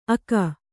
♪ aka